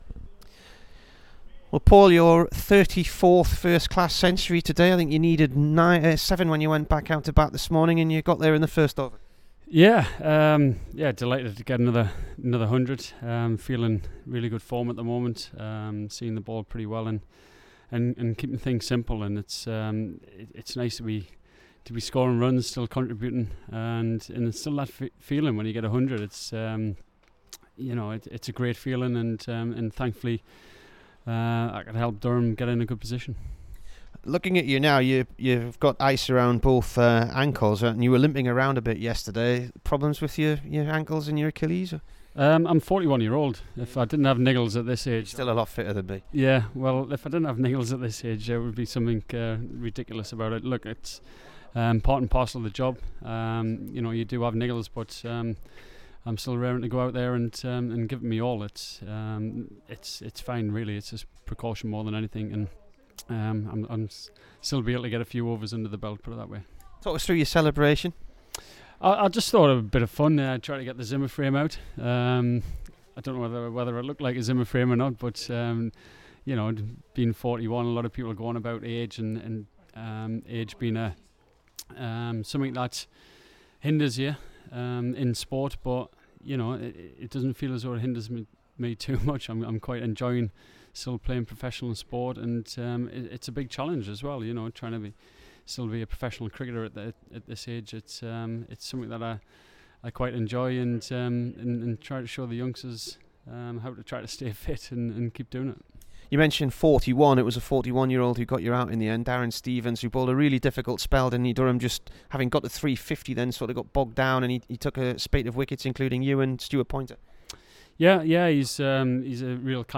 Here is the Durham skipper after he made 120 at Kent.